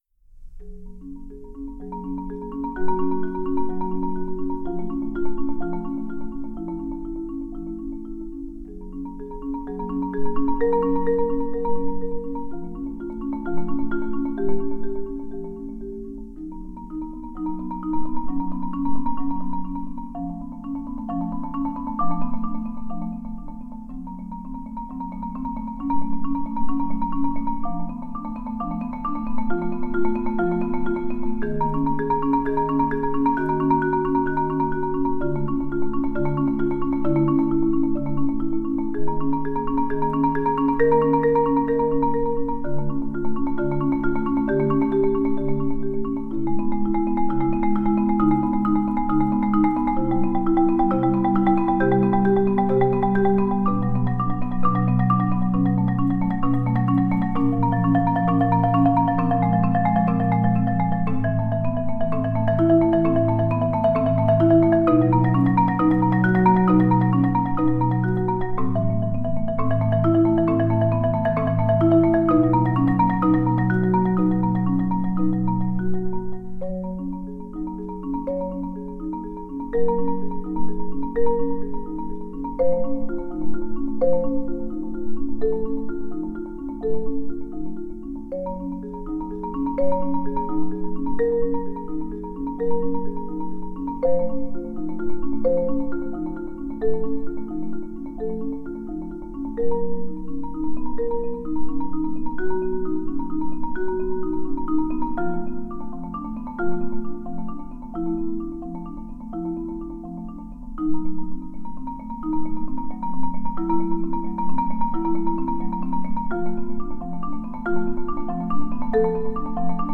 "Fading Time" for Solo Marimba
It is based on two sticking patterns that repeat over a slowly developing chord progression. This solo is intermediate difficulty and can fit on a "low-A" marimba.